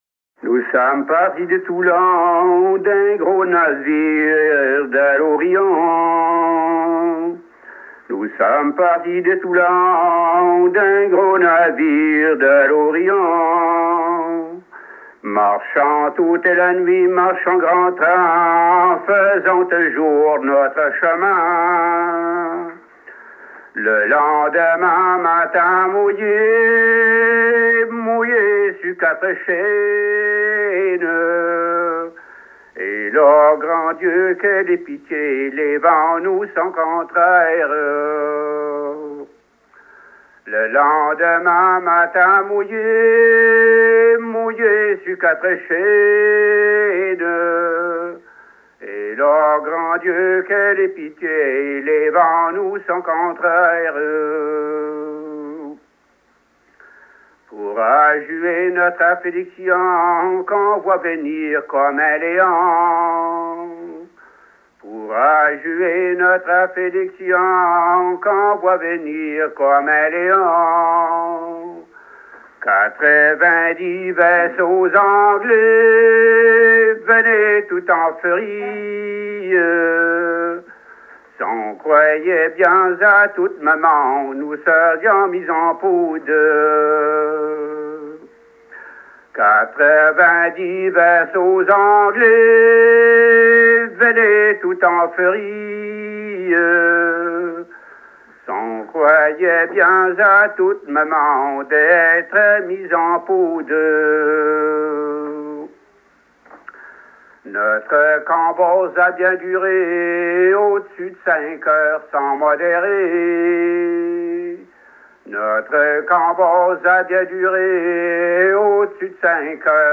Chantée
Edition discographique Chants et complaintes maritimes des terres françaises d'Amérique